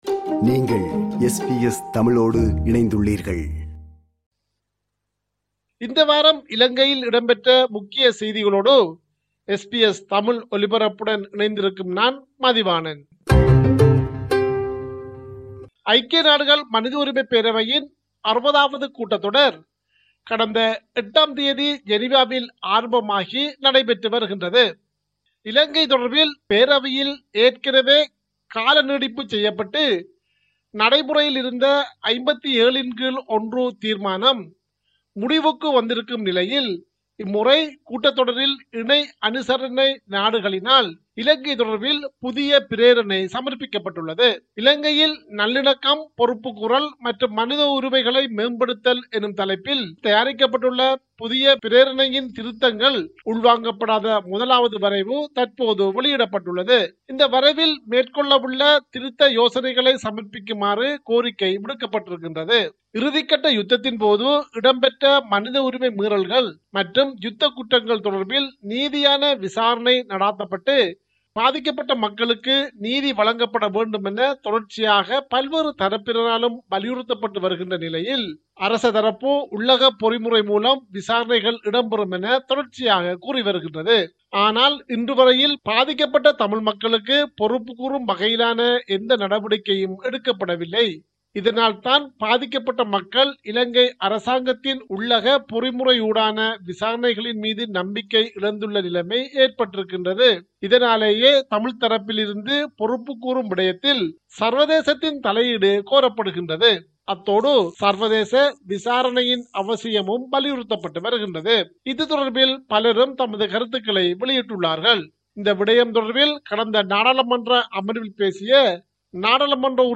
இலங்கை: இந்த வார முக்கிய செய்திகள்